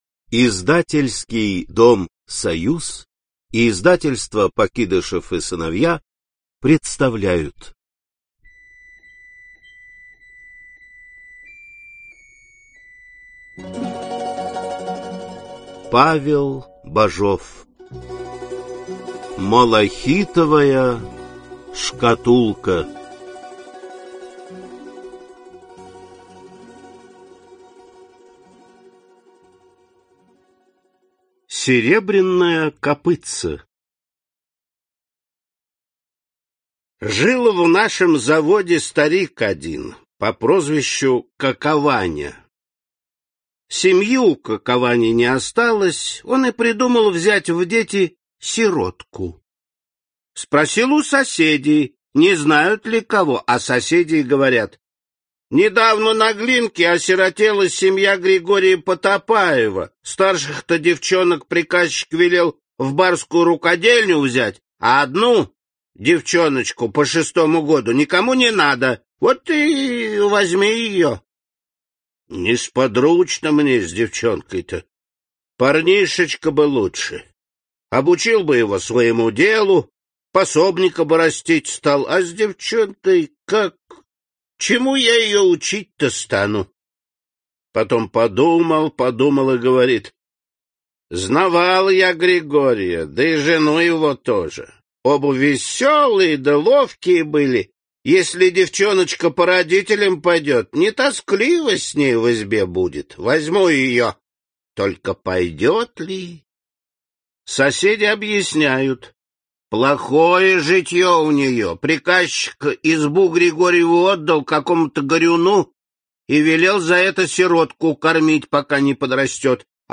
Аудиокнига Малахитовая шкатулка | Библиотека аудиокниг